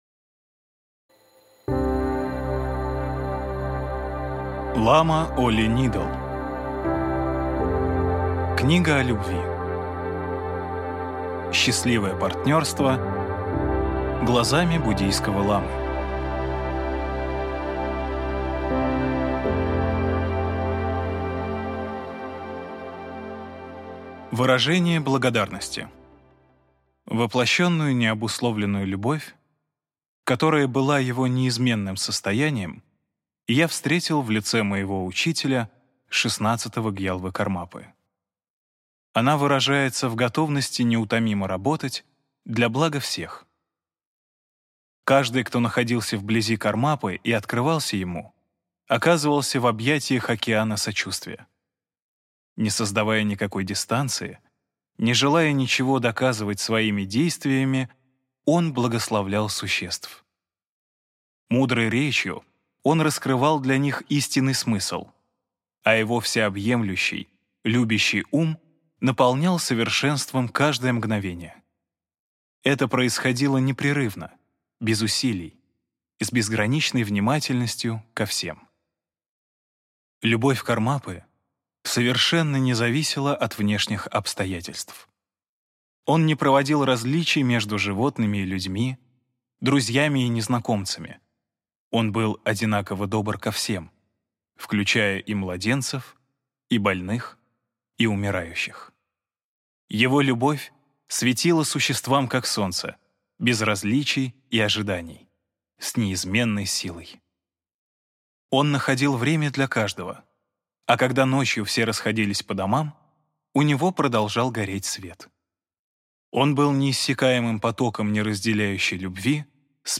Аудиокнига Книга о любви. Счастливое партнерство глазами буддийского ламы | Библиотека аудиокниг